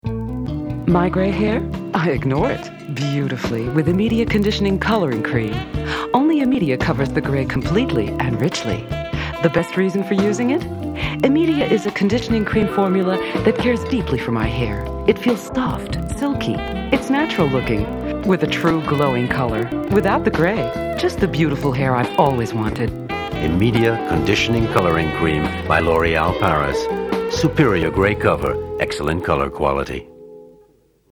Voix off
- Contralto Mezzo-soprano